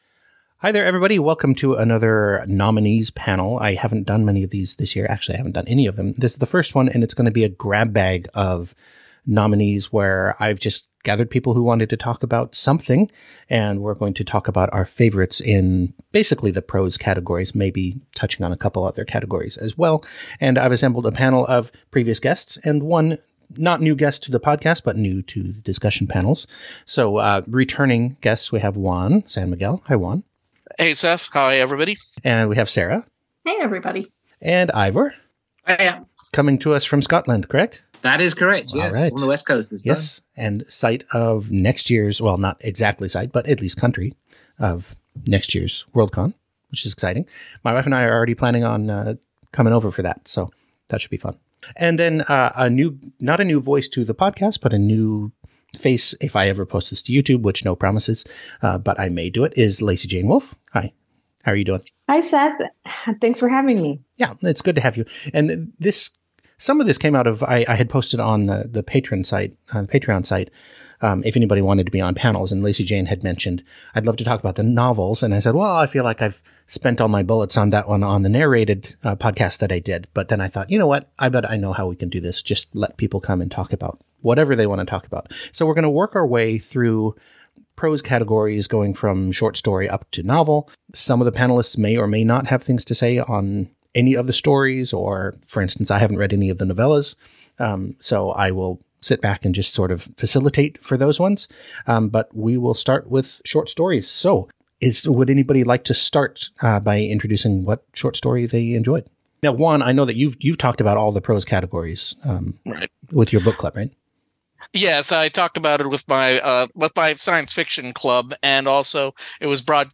2023 Fiction Nominees Panel!